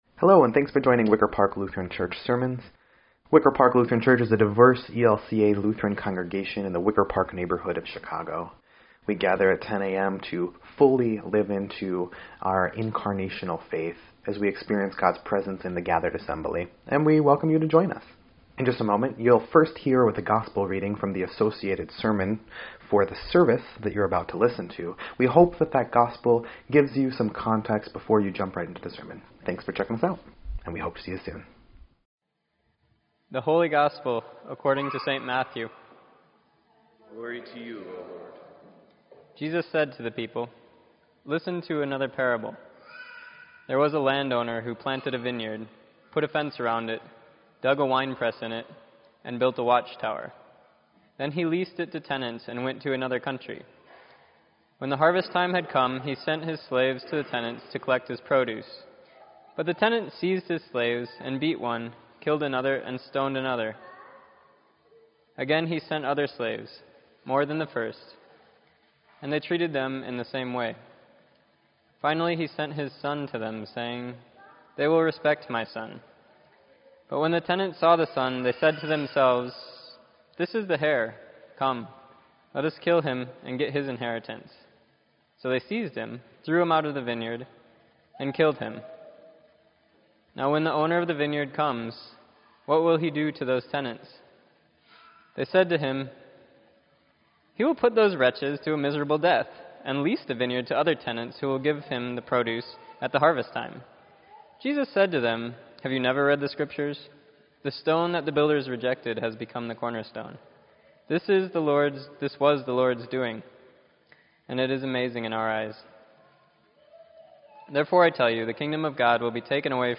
Sermon_10_8_17_EDIT.mp3